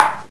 soft-hitfinish2.ogg